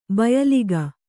♪ bayaliga